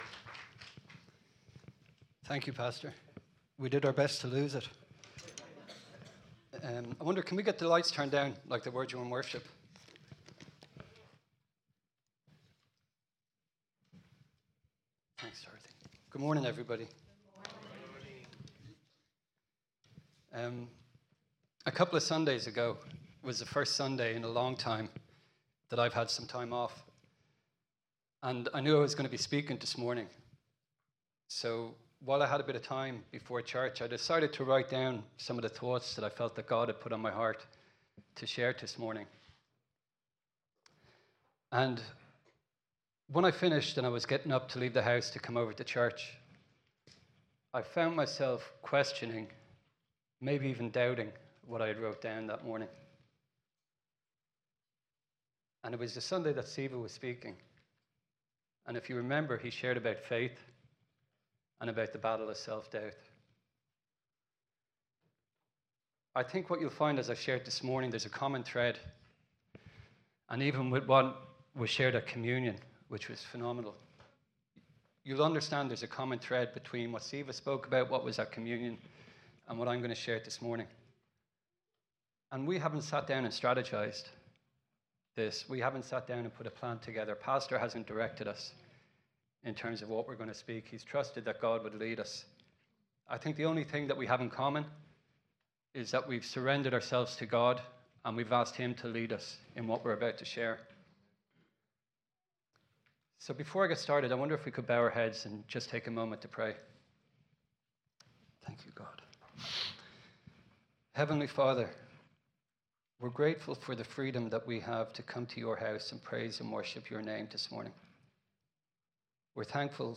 Sermons recorded during 2025